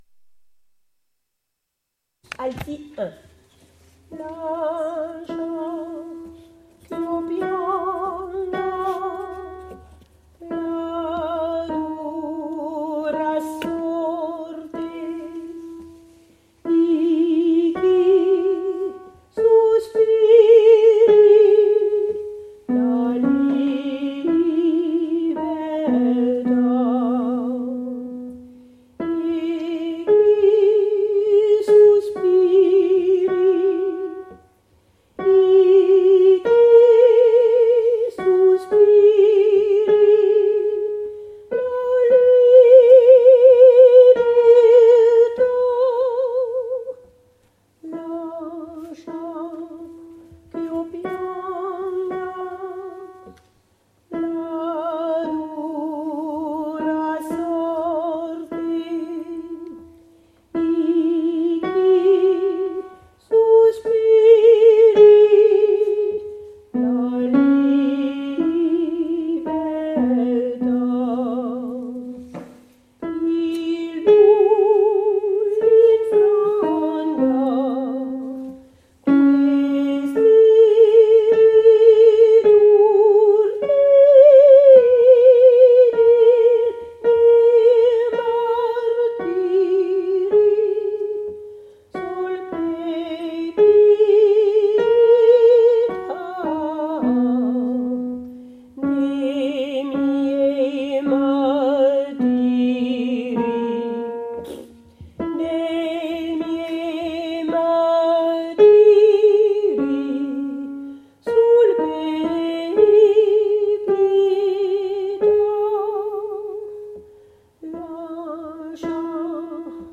alti 1